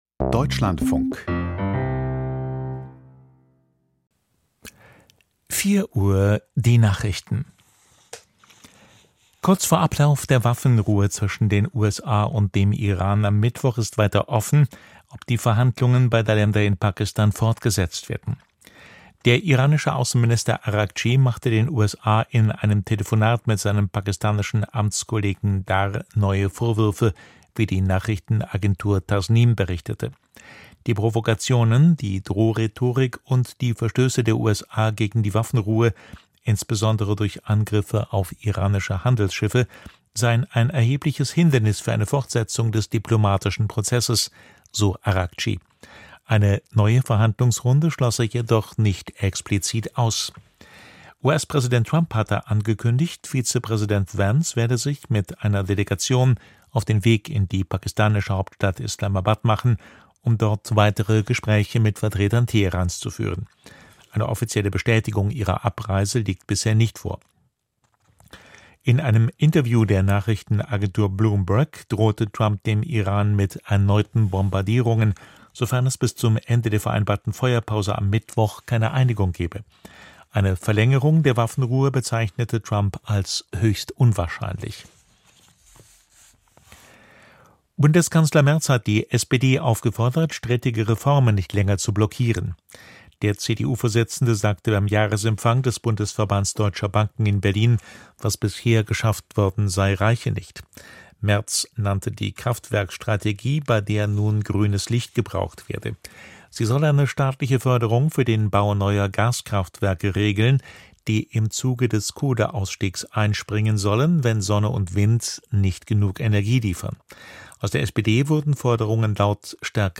Die Nachrichten vom 21.04.2026, 04:00 Uhr
Aus der Deutschlandfunk-Nachrichtenredaktion.